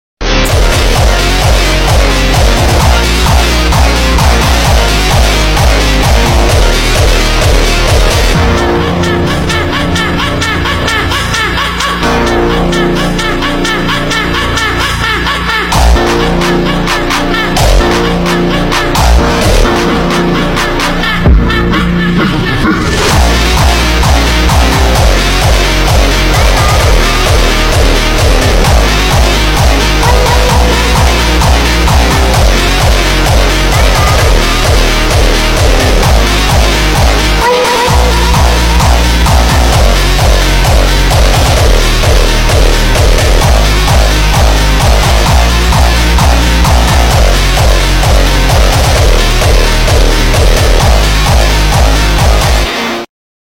phonk